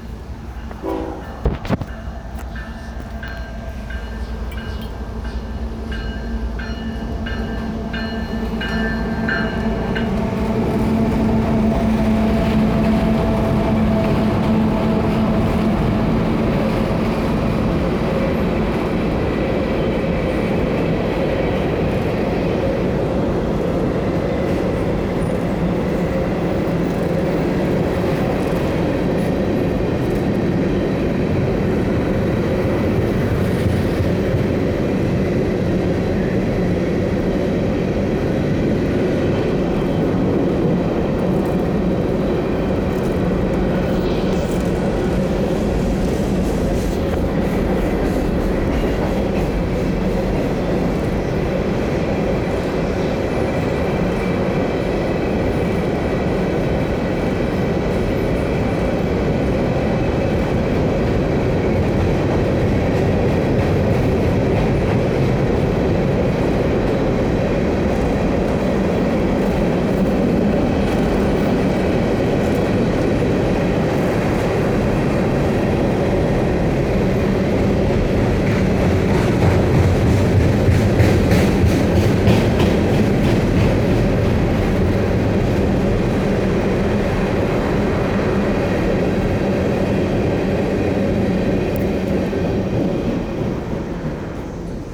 train.wav